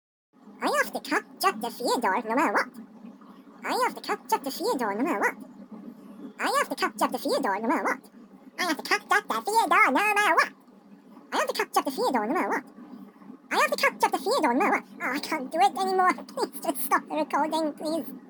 "Catch up to Theodore" outtakes 1.0.0
When you have fun doing voice acting, i guess all you do is record different takes.